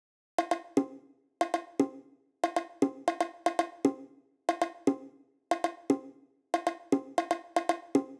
11 Bongos.wav